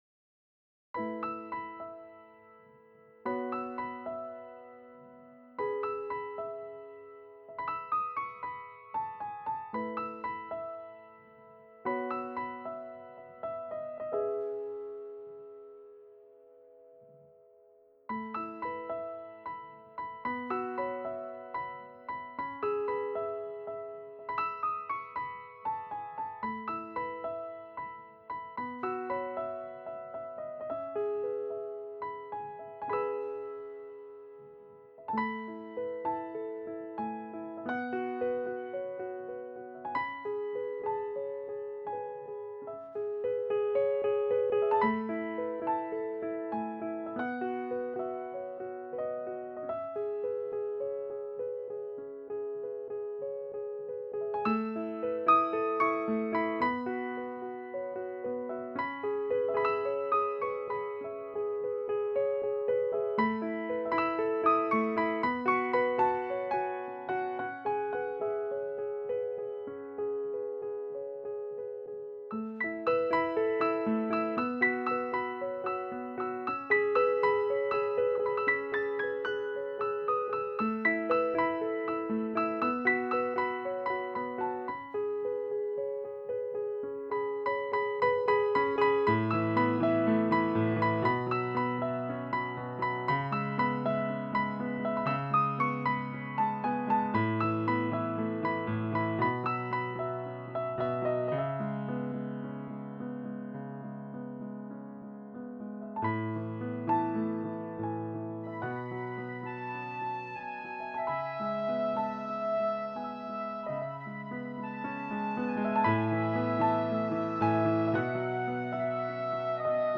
GenereBlues